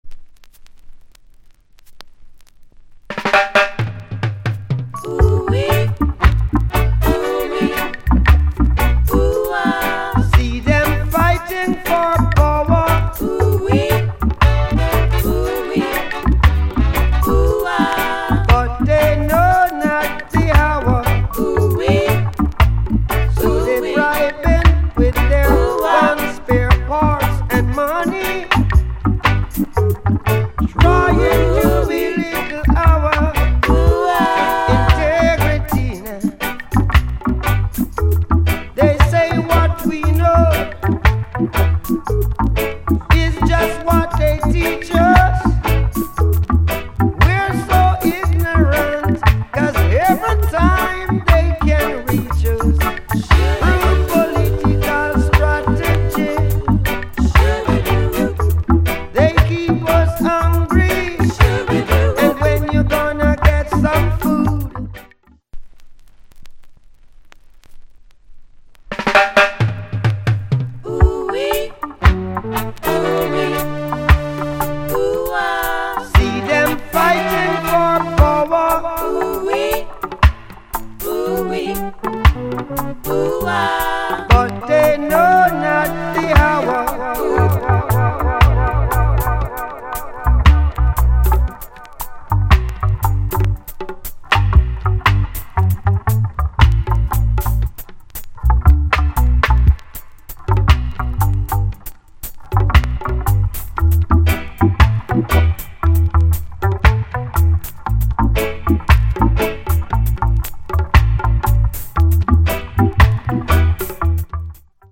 Roots Rock / Male Vocal Condition EX- Soundclip